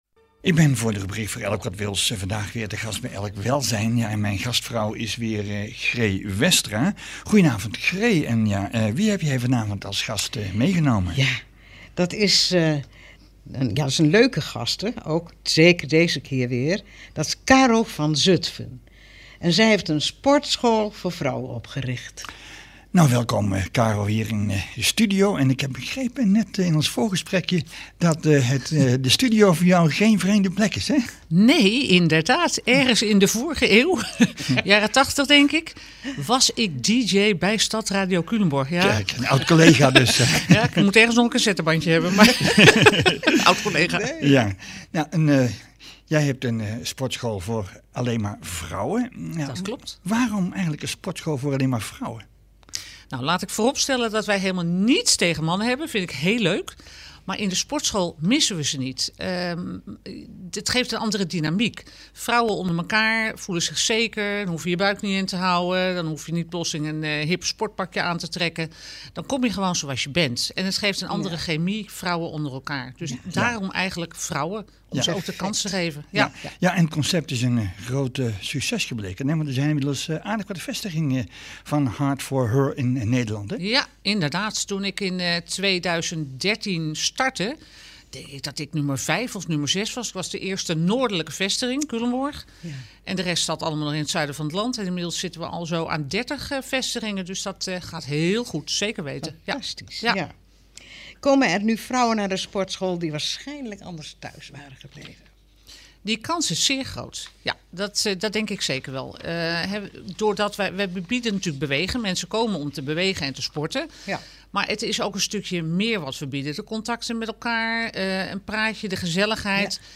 Interview SRC radio d.d. 15 februari 2024,